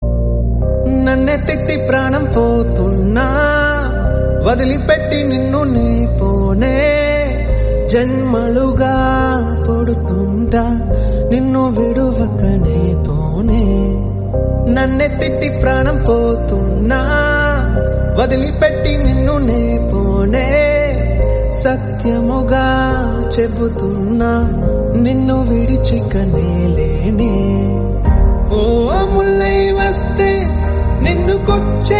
melody ringtone download